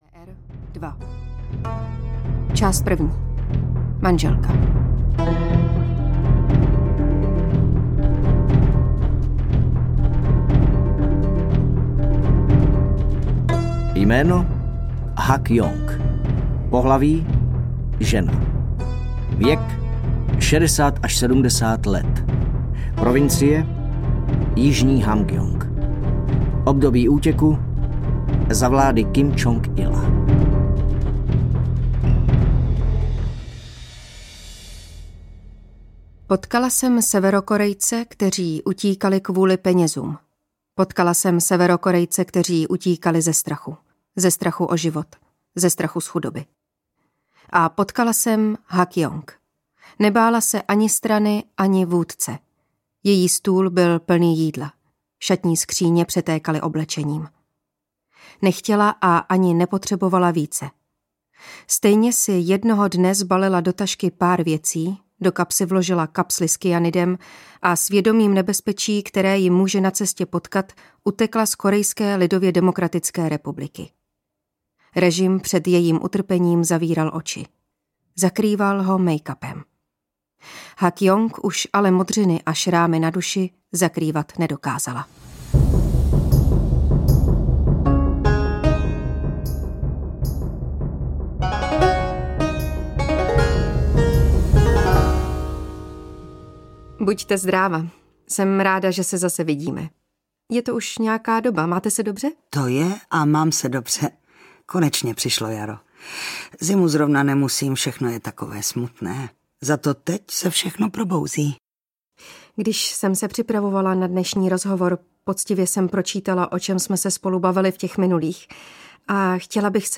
Svědectví o životě v KLDR 2 audiokniha
Ukázka z knihy